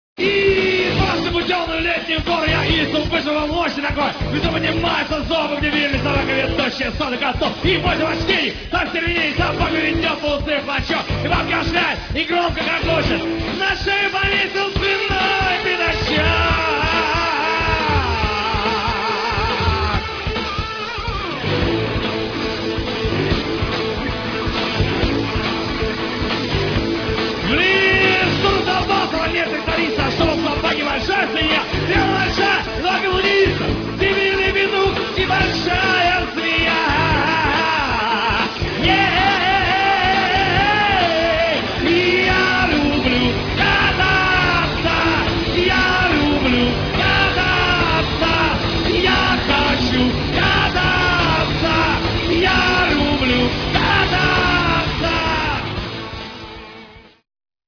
2-е Международное байк-шоу (30.08.96)
фрагмент песни ( 1 мин.)